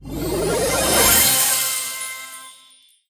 sfx_tap_meta_star_explode_01.ogg